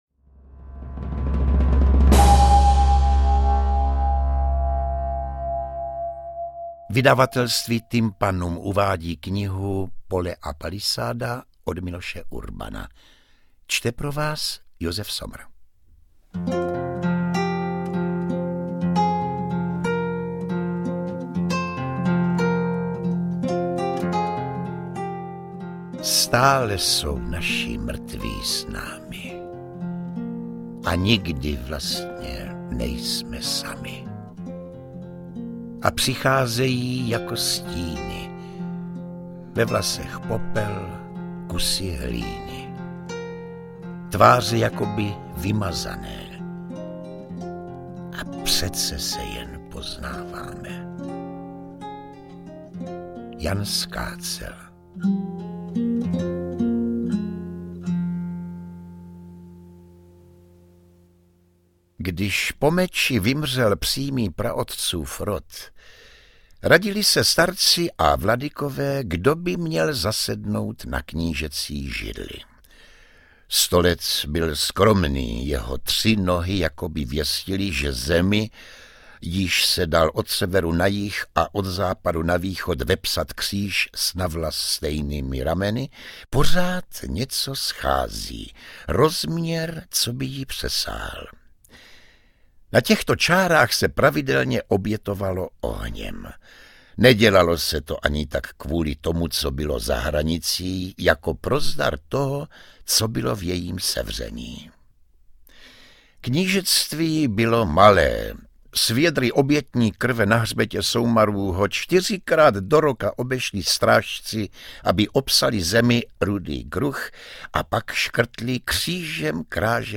Interpret:  Josef Somr
Audiokniha ve formátu MP3. Můžete se poslechnout plnou verzi knihy, ve které autor svébytně zpracoval legendu o Libuši a Přemyslovi, v podání Josefa Somra.